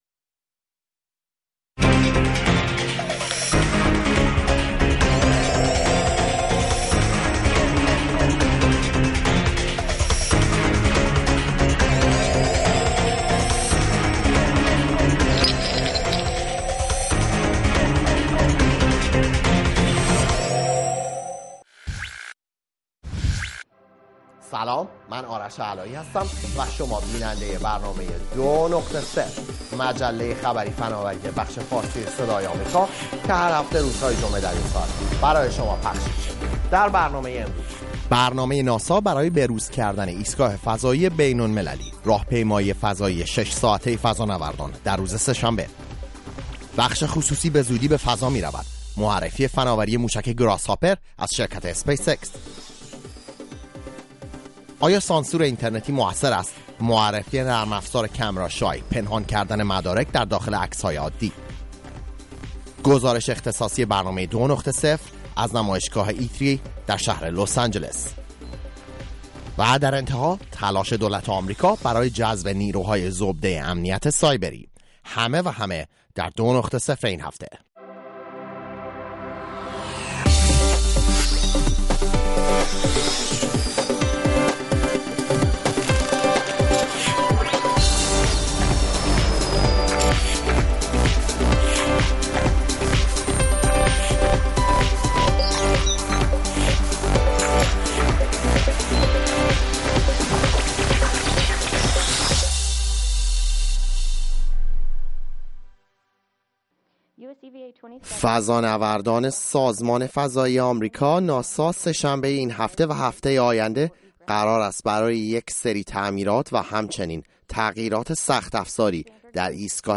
روی خط برنامه ای است برای شنیدن نظرات شما. با همفکری شما هر شب یک موضوع انتخاب می کنیم و شما می توانید از طریق تلفن، اسکایپ، فیس بوک یا ایمیل، به صورت زنده در بحث ما شرکت کنید.